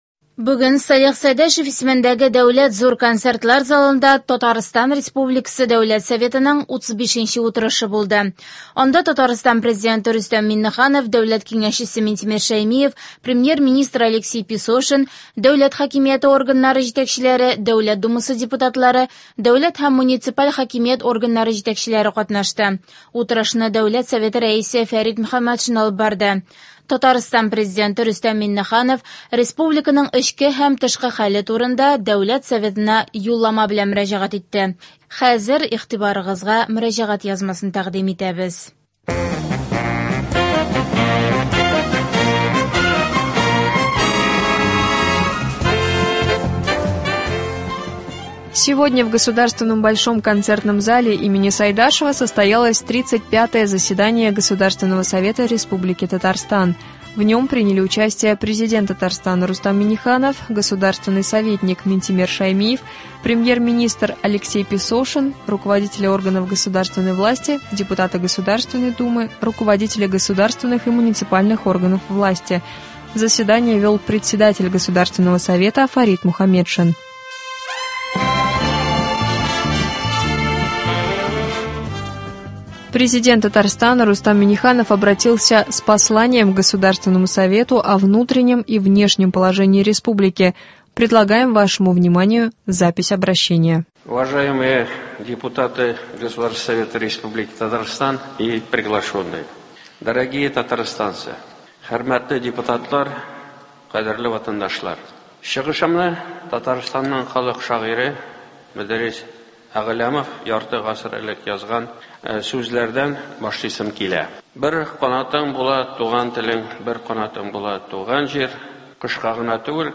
Сегодня в Государственном Большом концертном зале имени Сайдашева состоялось 35 заседание Государственного совета республики Татарстан.
Заседание вел Председатель Государственного Совета Фарид Мухаметшин.